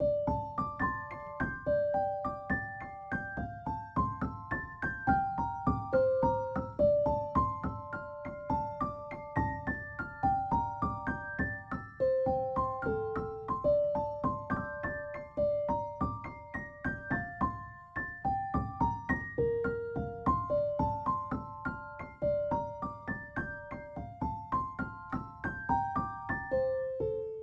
03 highpiano.ogg